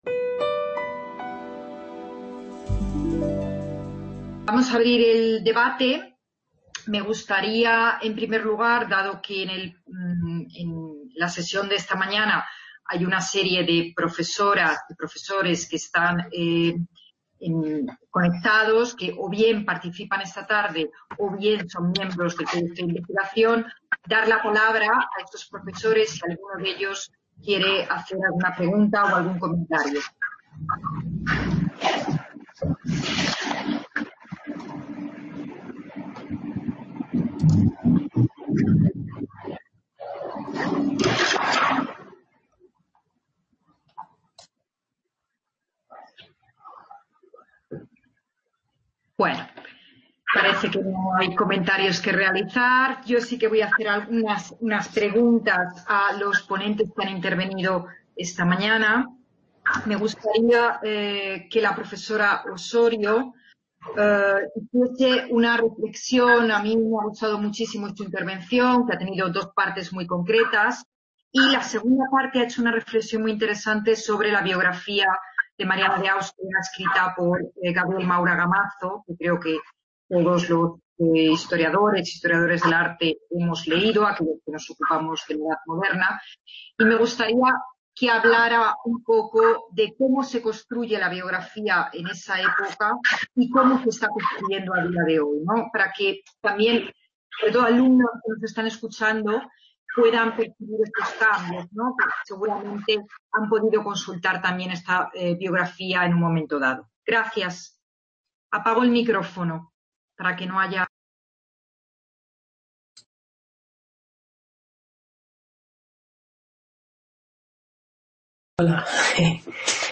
Debate.